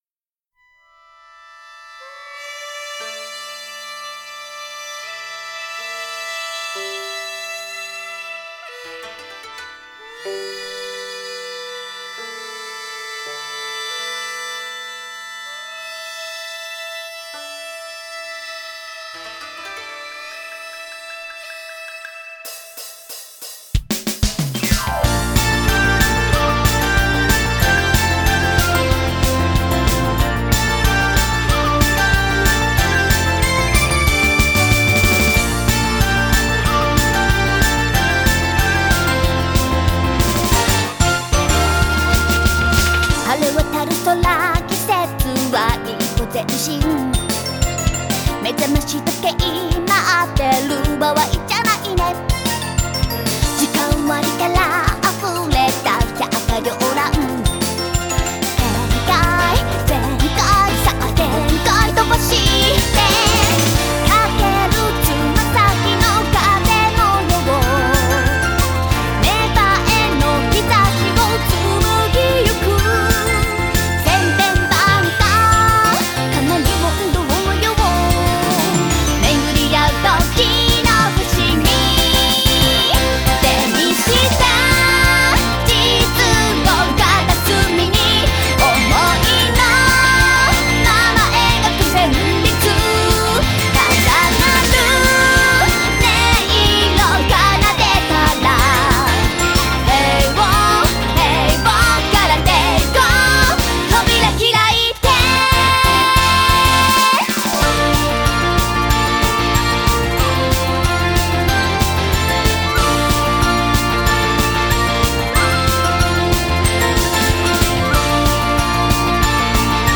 ボーカル